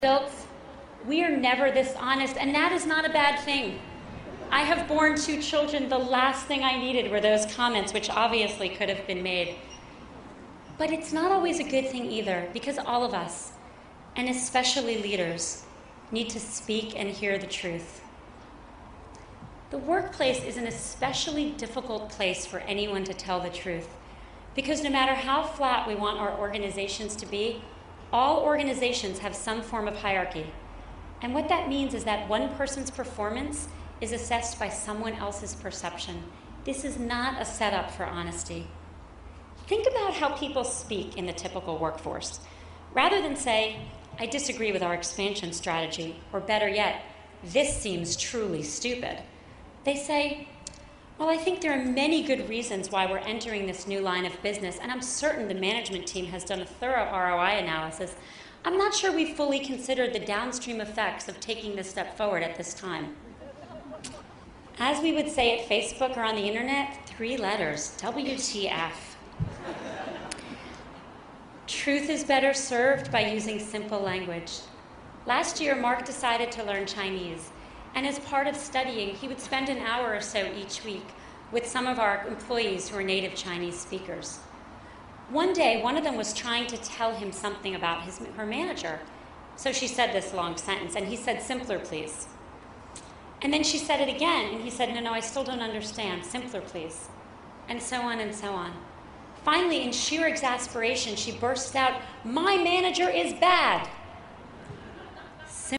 公众人物毕业演讲 第182期:桑德伯格2012哈佛商学院(7) 听力文件下载—在线英语听力室